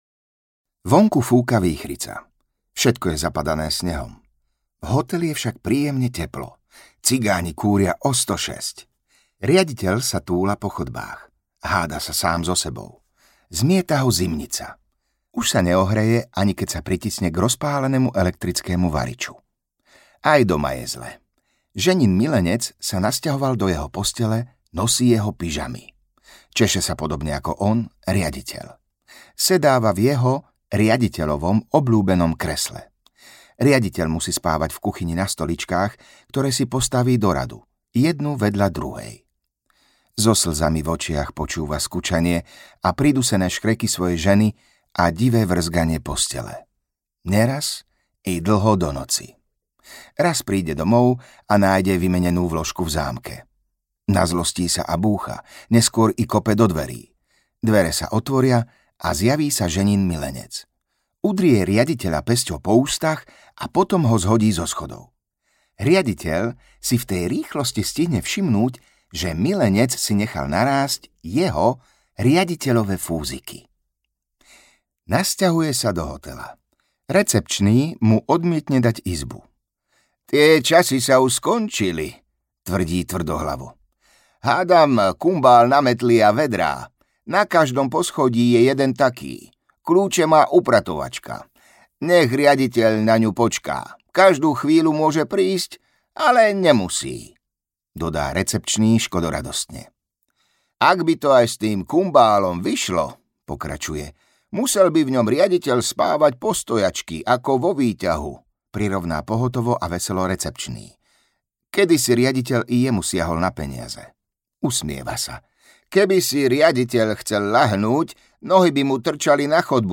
Rivers Of Babylon audiokniha
Ukázka z knihy
rivers-of-babylon-audiokniha